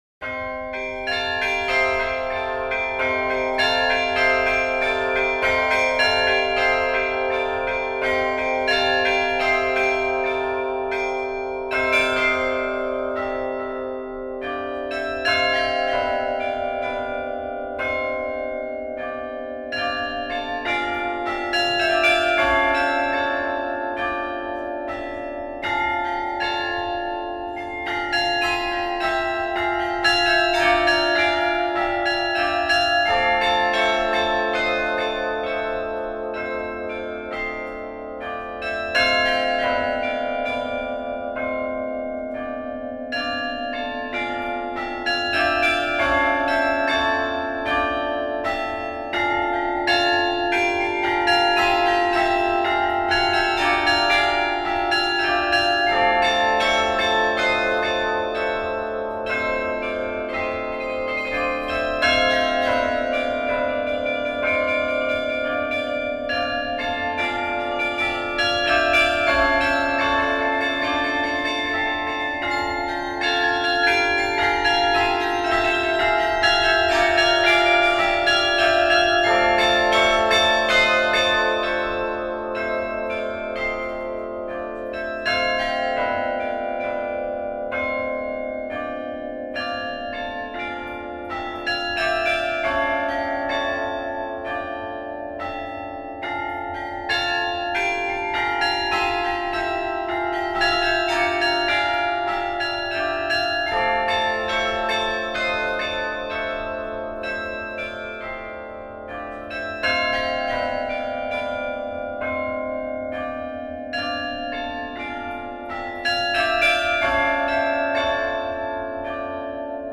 Le carillon
L'absence quasi-totale d'épigraphie donne un son pur, juste et limpide.